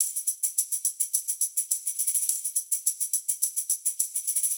Index of /musicradar/sampled-funk-soul-samples/105bpm/Beats